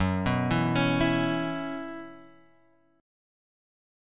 F#7sus4 chord {2 2 2 x 2 0} chord